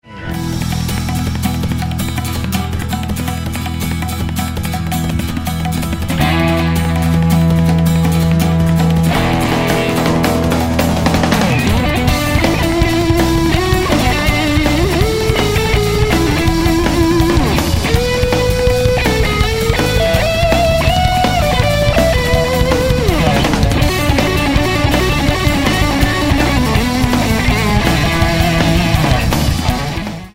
acoustic & electric guitars
drums
keyboards, lyricon, tenor saxophone
electric bass, vocals